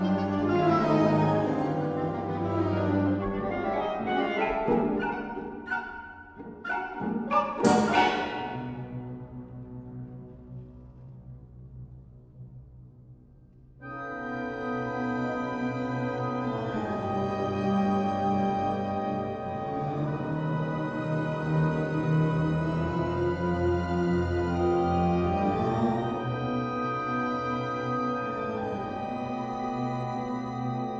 "templateExpression" => "Musique classique"
"templateExpression" => "Musique orchestrale"